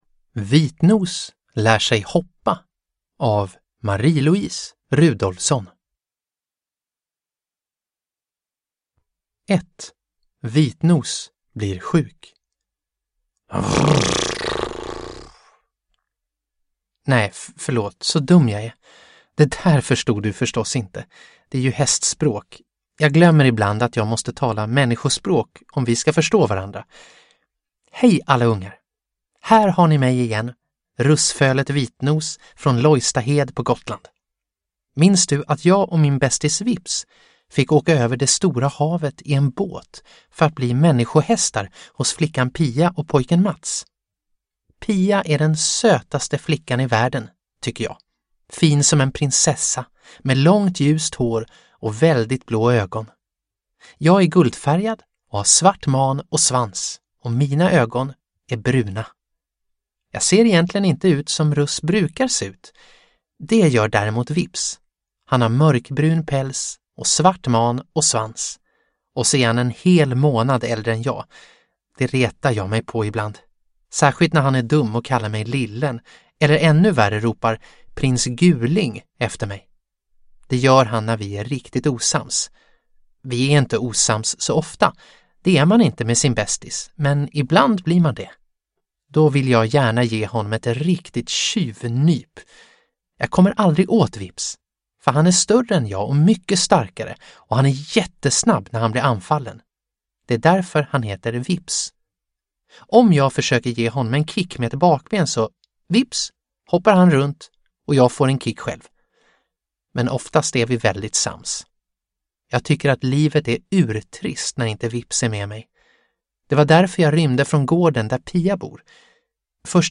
Vitnos lär sig hoppa – Ljudbok – Laddas ner